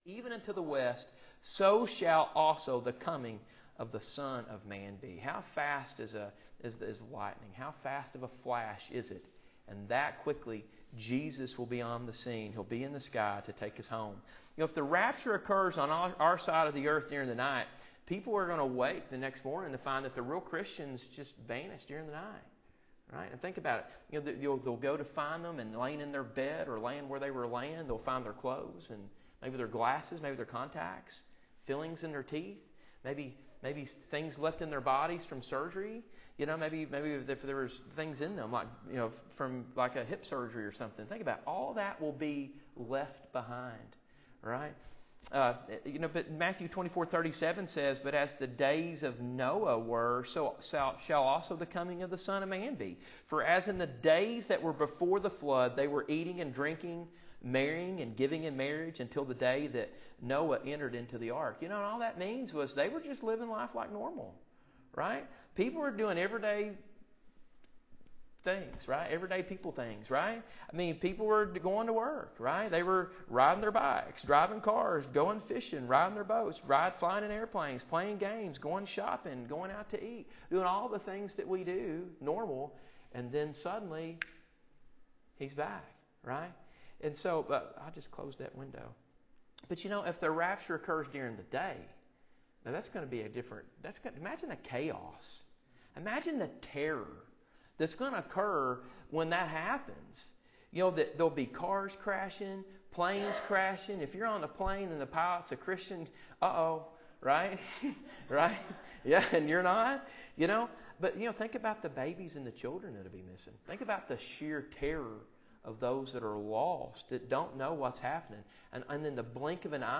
Passage: I Corinthians 15:51-58 Service Type: Wednesday Night https